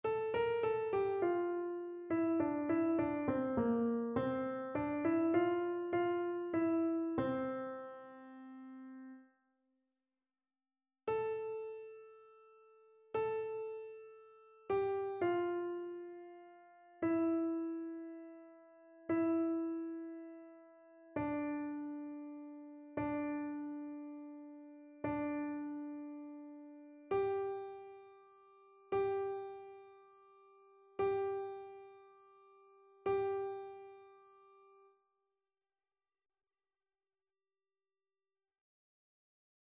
Alto
annee-b-temps-de-noel-bapteme-du-seigneur-cantique-d-isaie-alto.mp3